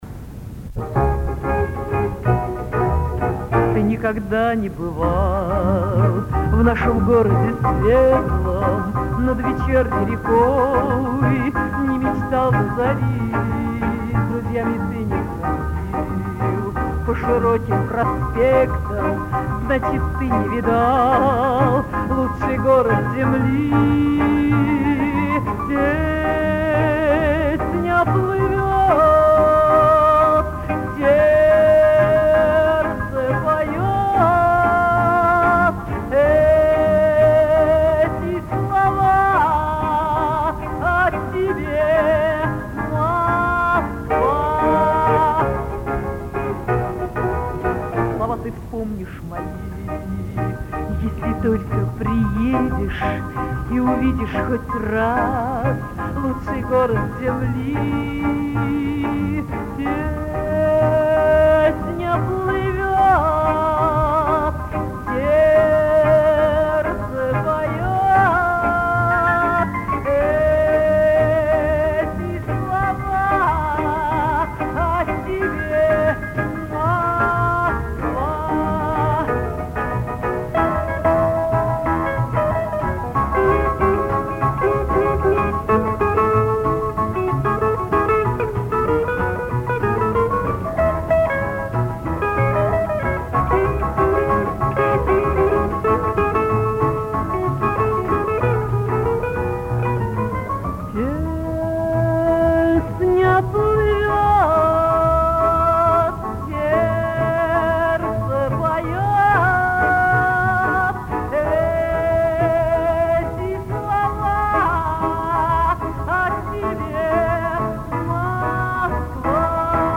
С аудиокассеты (появилась на совмузыке).